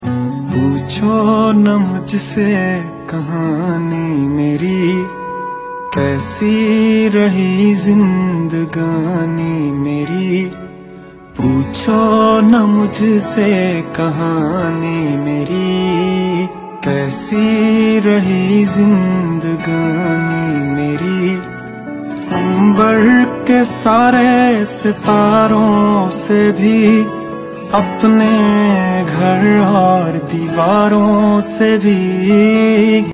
silky smooth vocals
adds a dash of guitar magic to the mix